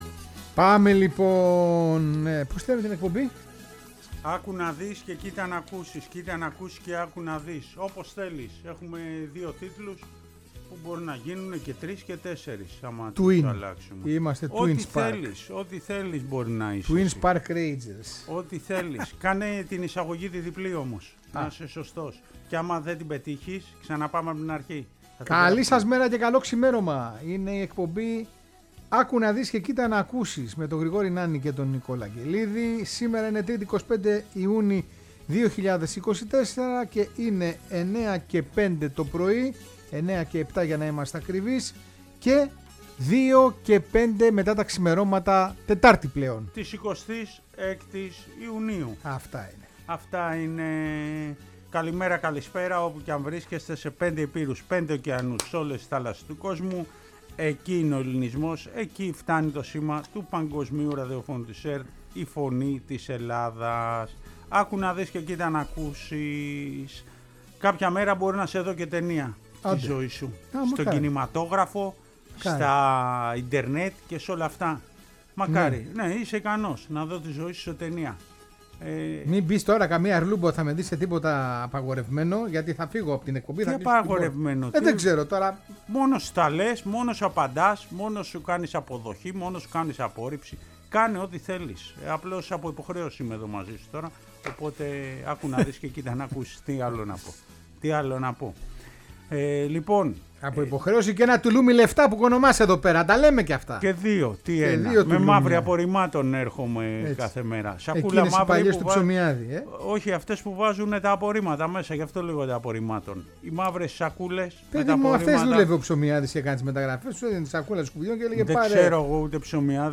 Η σατυρική εκπομπή που ξεπερνάει το όρια της ενημέρωσης και ασχολείται με τα καλώς και κακώς κείμενα του αθλητισμού και όχι μόνο…
μαθαίνουμε την ιστορία και ακούμε τον ύμνο της Νίκης Σητείας